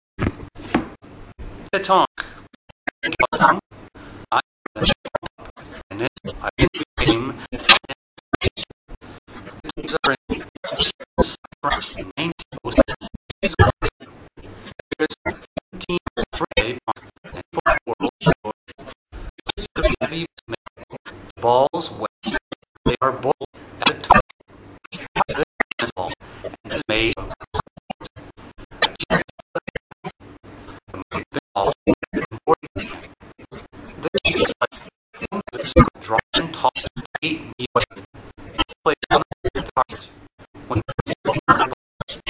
42 Petanque ＲＥＡＤＩＮＧ
(slow)